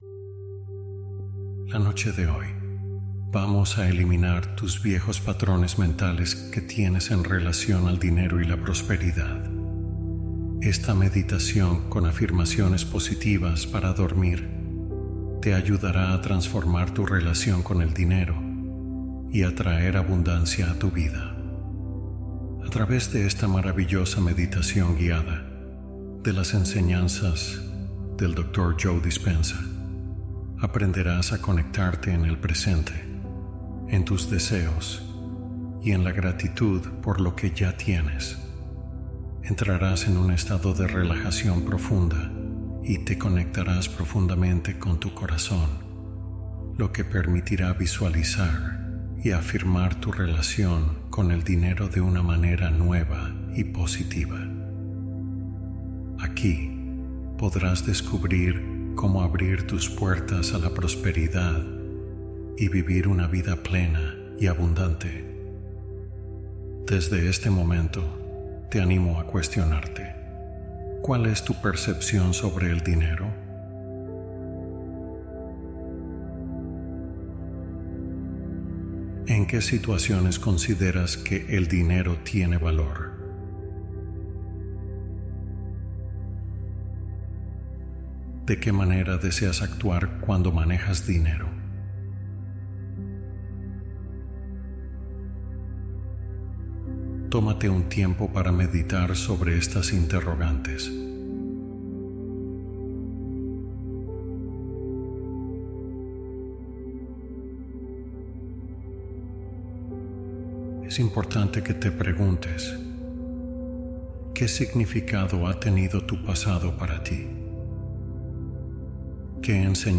Duérmete Asumiendo Tu Deseo Cumplido | Meditación Nocturna